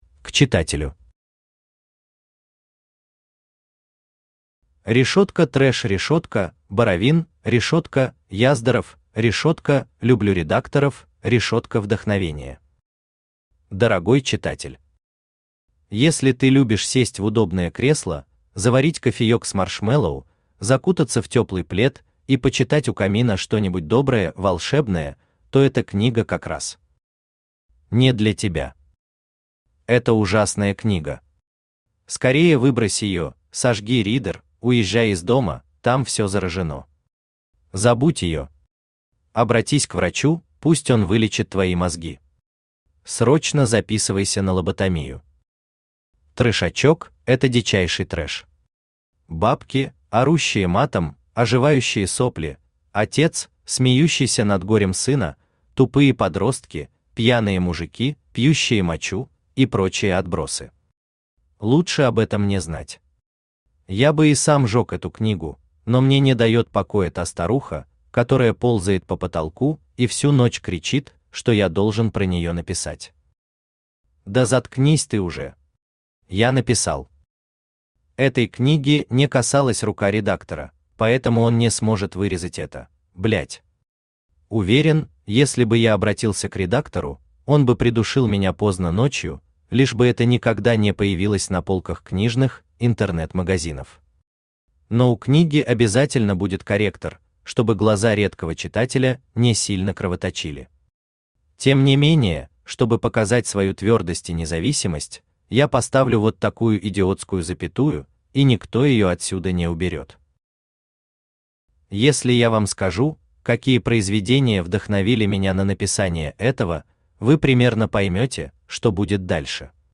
Аудиокнига Трэшачок. Сборник рассказов | Библиотека аудиокниг
Сборник рассказов Автор Олег Боровин Читает аудиокнигу Авточтец ЛитРес.